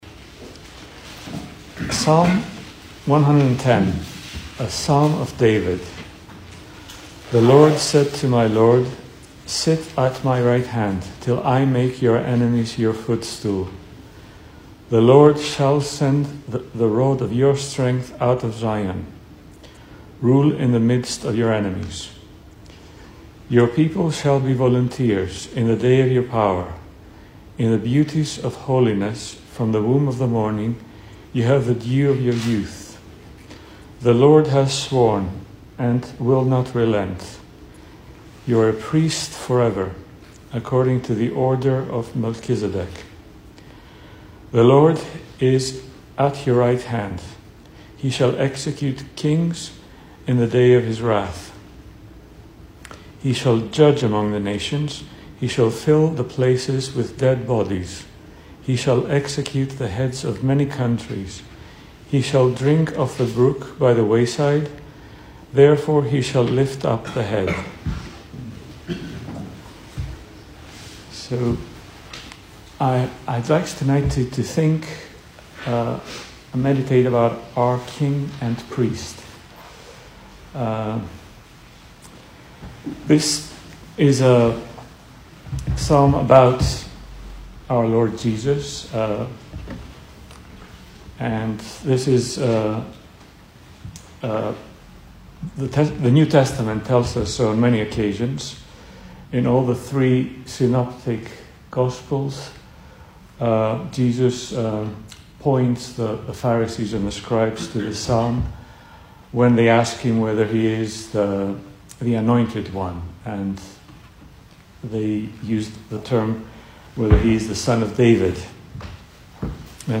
Service Type: Weekday Evening